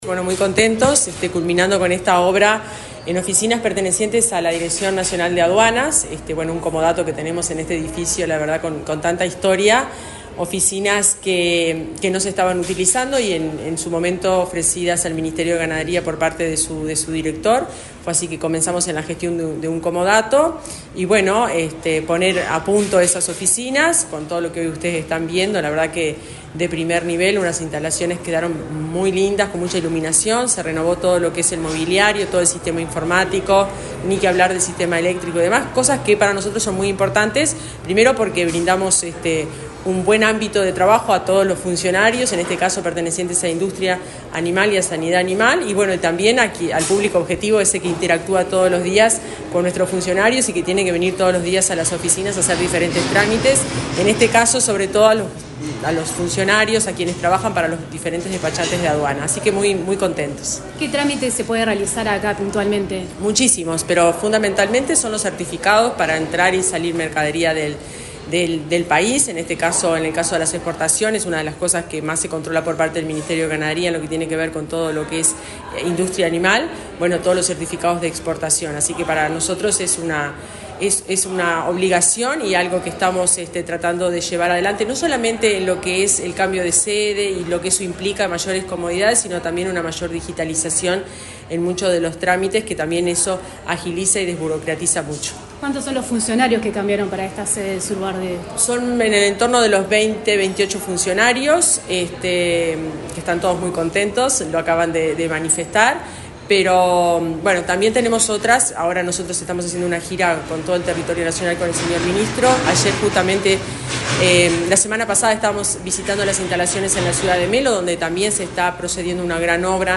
Declaraciones de la directora general del MGAP, Fernanda Maldonado